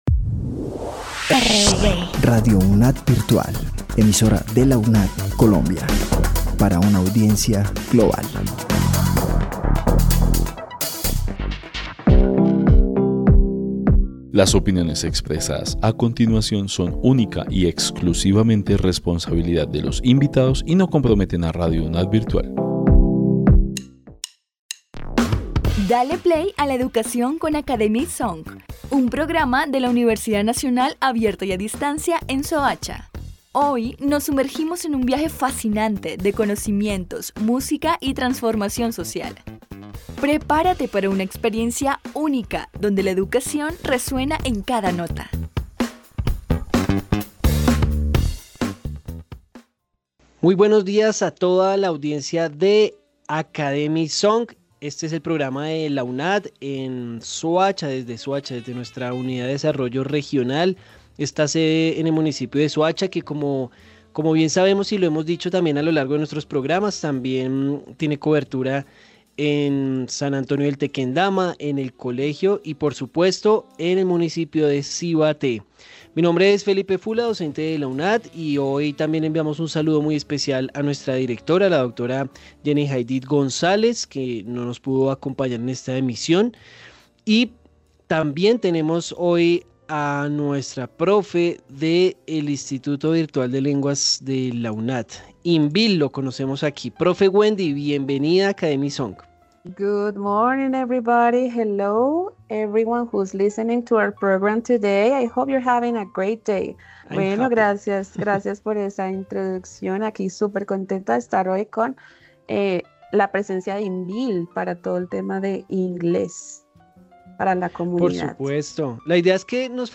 Academia y Song en la U, es un programa radial de la UDR Soacha, donde docentes y estudiantes discuten temas de interés para la comunidad, destacando a la UNAD como motor de desarrollo y transformación social. El programa incluye un tema del día, secciones sobre el trabajo de la UNAD en la región, análisis de temas actuales, y relatos inspiradores de la comunidad académica. Además, la música que acompaña el programa, vincula artistas, géneros o estilos con el tema principal.